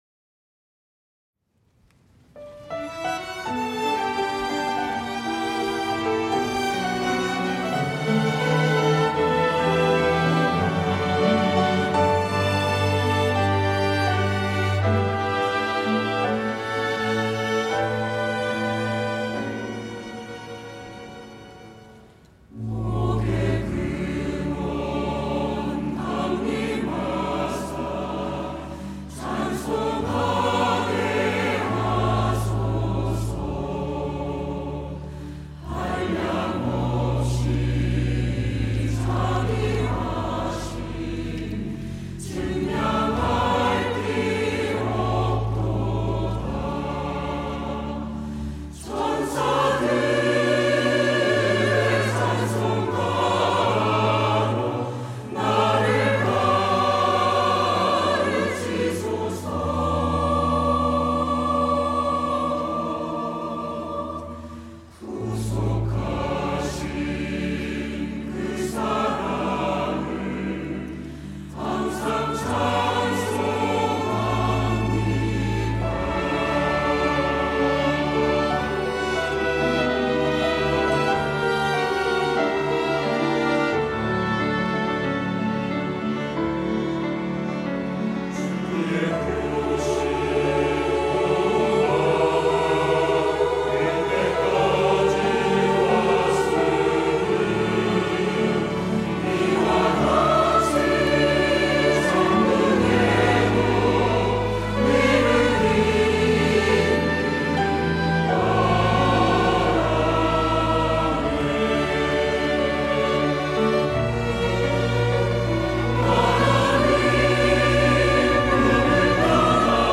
호산나(주일3부) - 복의 근원 강림하사
찬양대